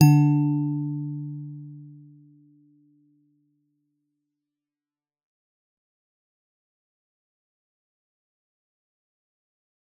G_Musicbox-D3-f.wav